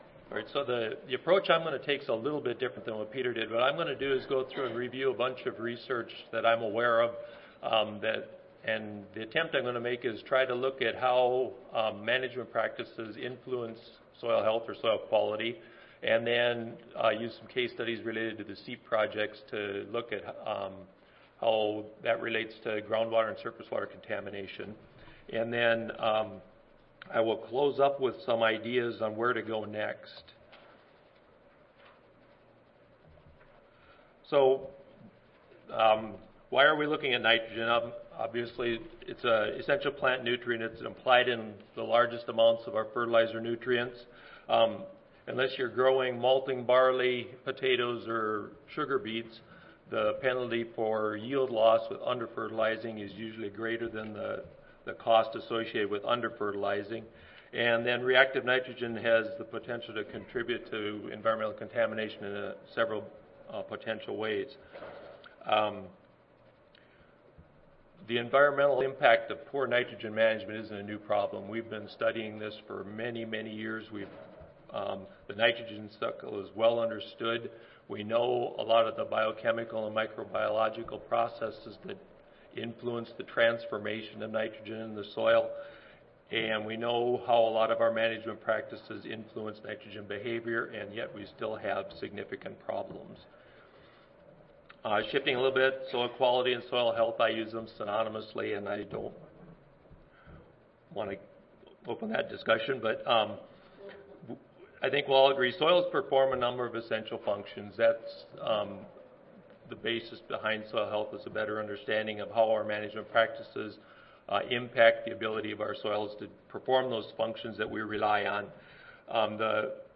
See more from this Division: ASA Section: Environmental Quality See more from this Session: Symposium--Field Management for Improved Soil Health and Environmental Quality
Recorded Presentation